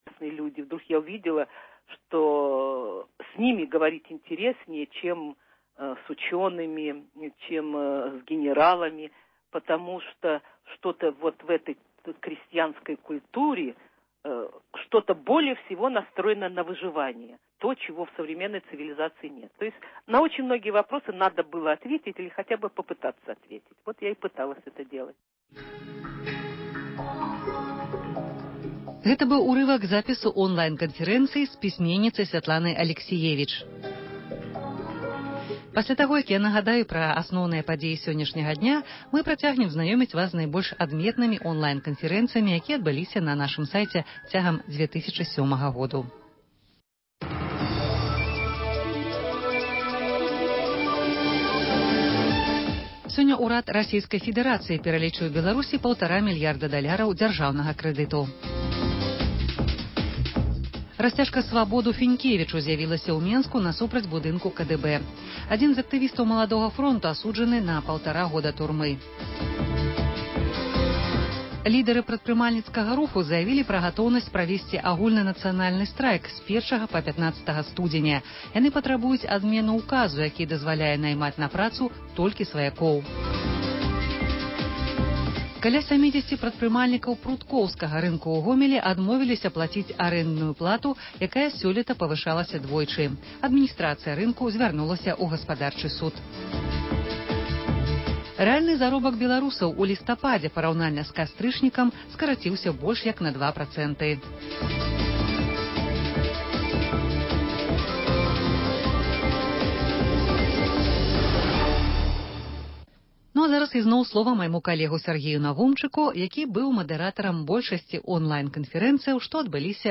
Званкі на Свабоду